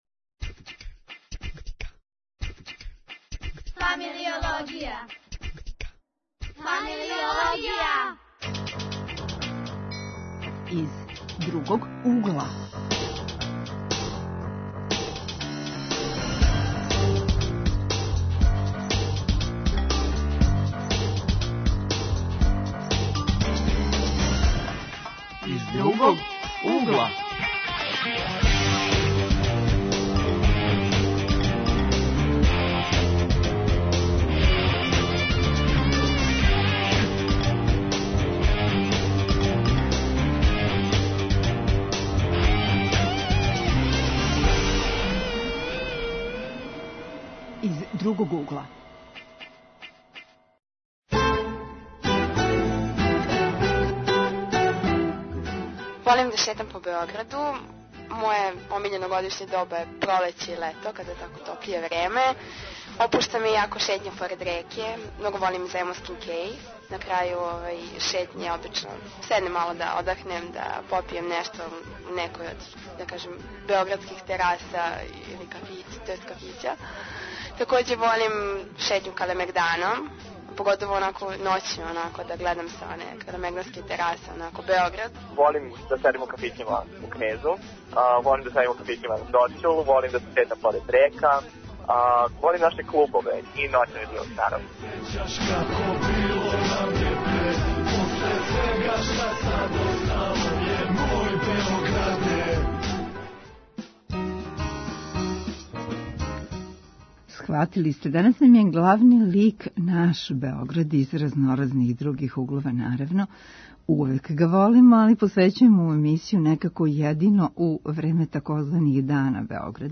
Гости у студију биће студенти којима је Београд родни град али и они којима није .